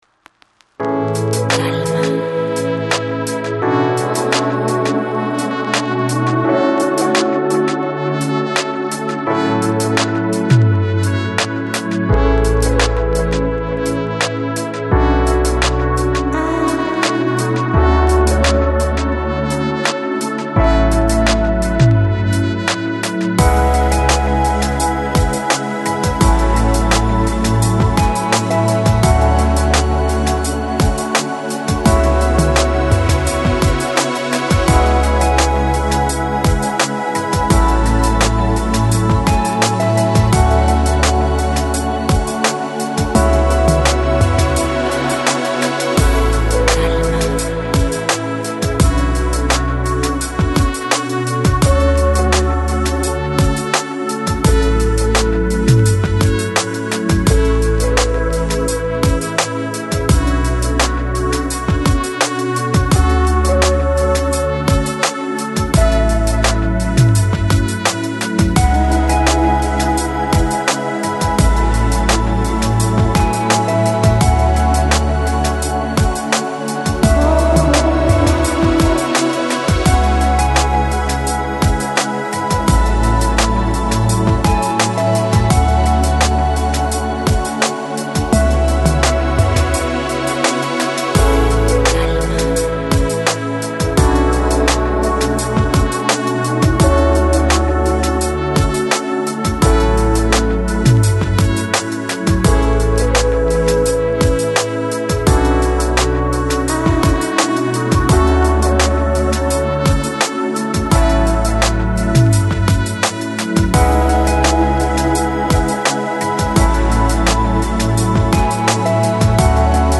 Electronic, Easy Listening, Lounge, Nu Jazz